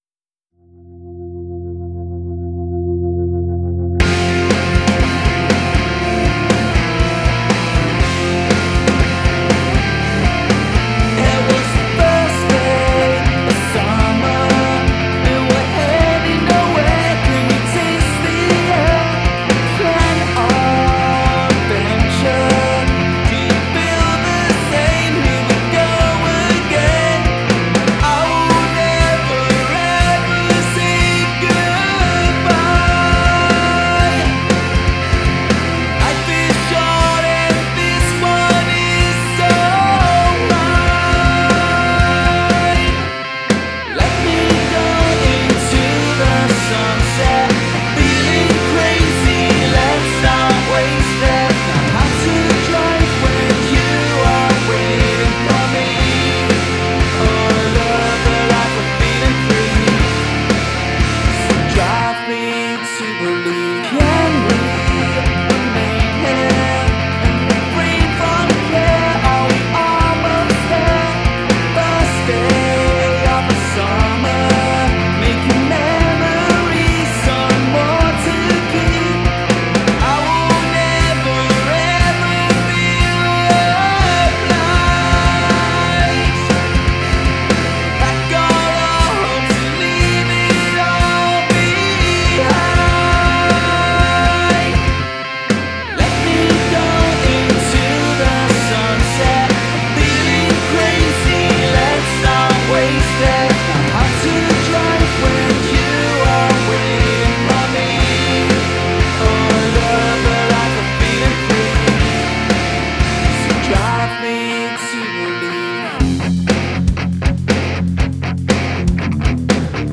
So the song feels up beat & always lifts my spirits when I hear it.